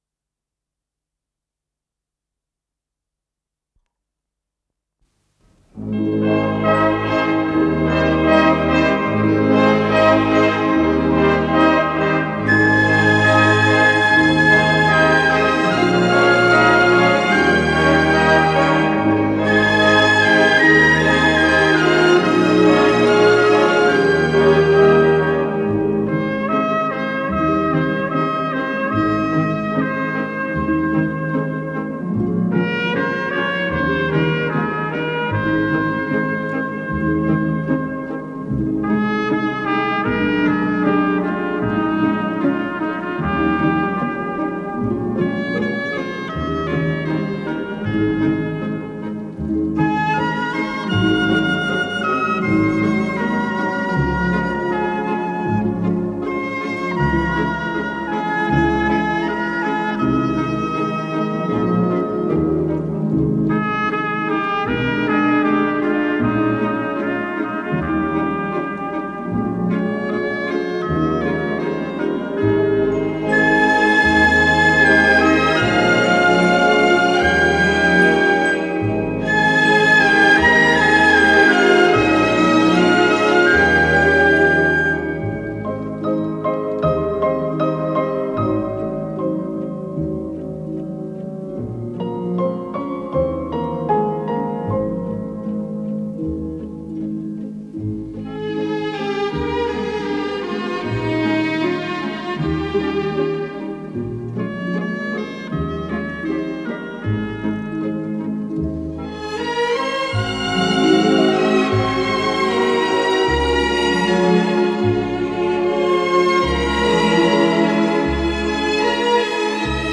ПодзаголовокЗаставка, ми бемоль мажор
ВариантДубль моно